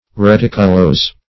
Search Result for " reticulose" : The Collaborative International Dictionary of English v.0.48: Reticulose \Re*tic"u*lose`\, a. Forming a network; characterized by a reticulated sructure.